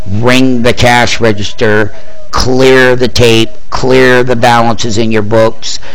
Cash register